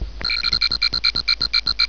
frogsong[1] (2).wav